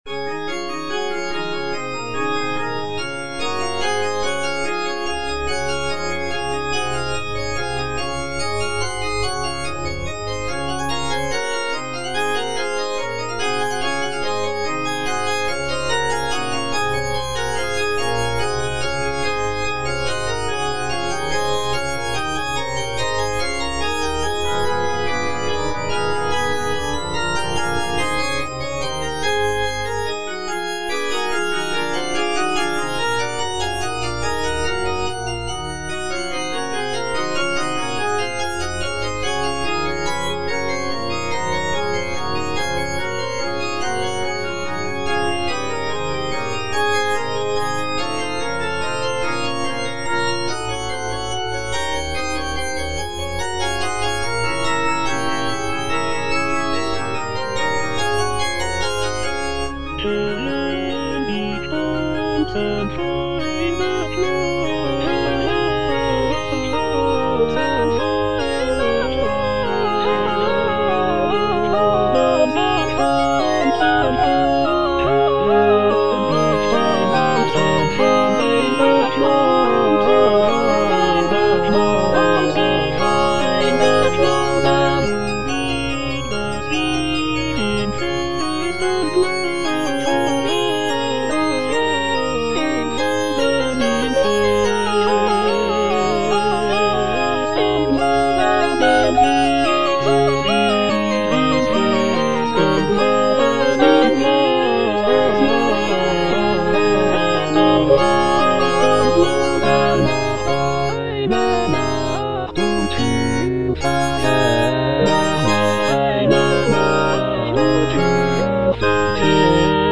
J.S. BACH - CHRISTMAS ORATORIO BWV248 - CANTATA NR. 6 (A = 415 Hz) 54 - Herr, wenn die stolzen Feinde schnauben (All voices) Ads stop: auto-stop